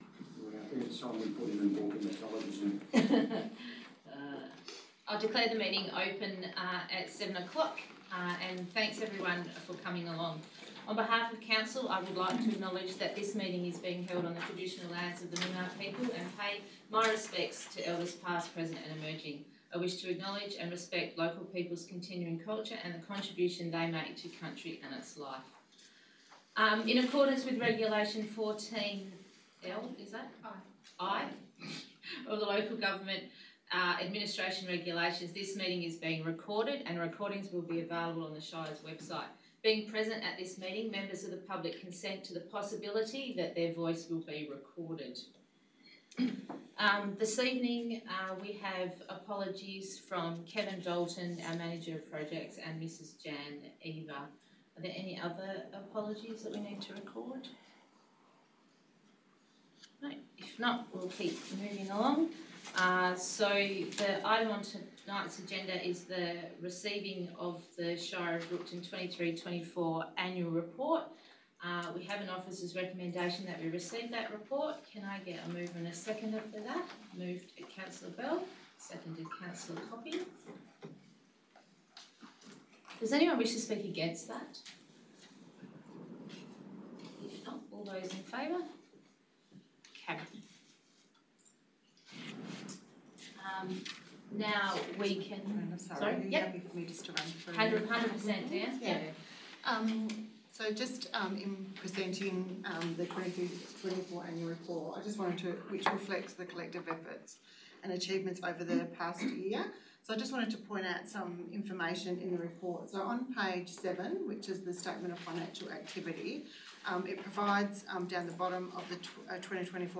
4-february-2025-annual-electors-meeting-recording.mp3